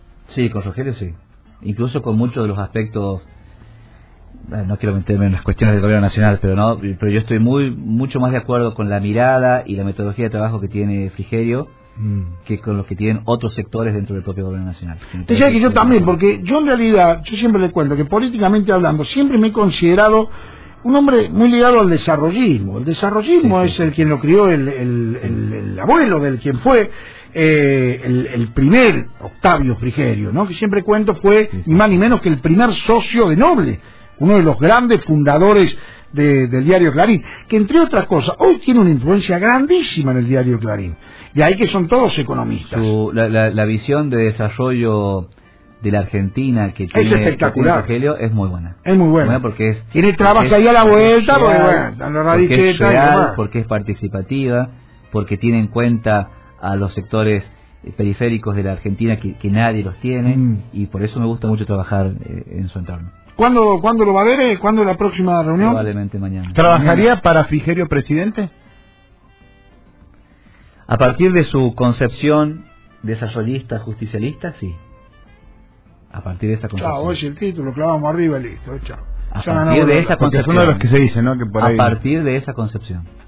En declaraciones a Radio 24 de La Rioja, Paredes Urquiza, el jefe comunal justicialista se mostró partidario del estilo de trabajo de Frigerio.